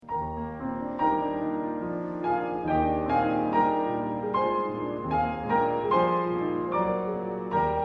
Sheep May Safely Graze (Transferred for two pianos by Mary Howe, Oxford University Press)